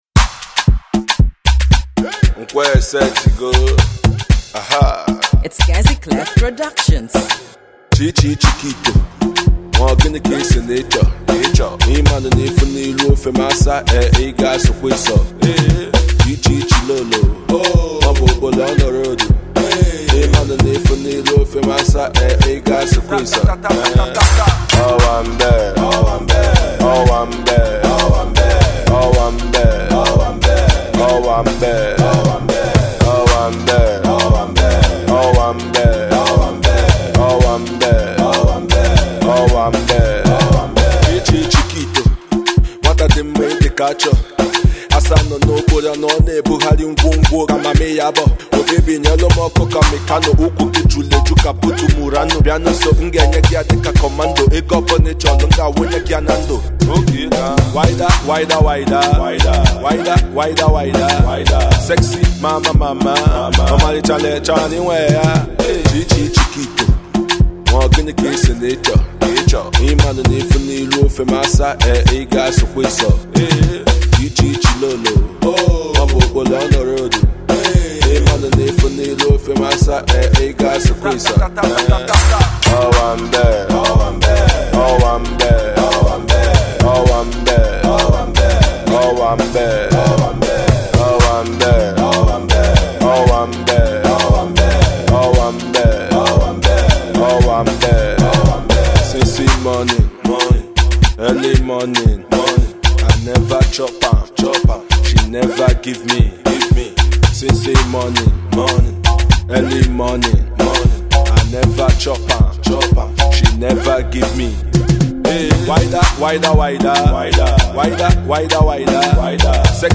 Afro-pop
has a minimalist but progressive vibe